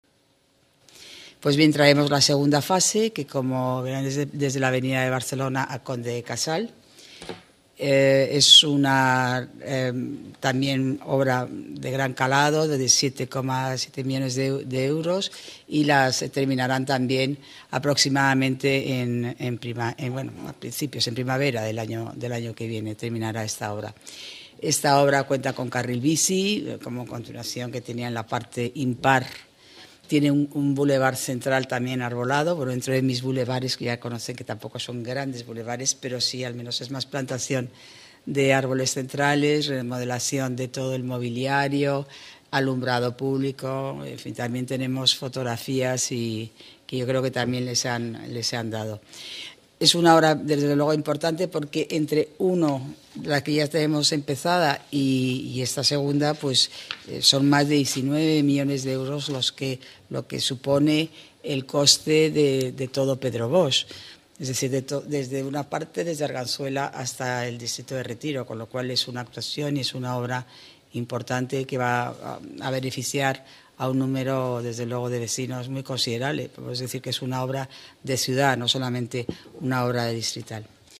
Nueva ventana:Declaraciones de la delegada de Obras y Equipamientos, Paloma García, durante la rueda de prensa posterior a la Junta de Gobierno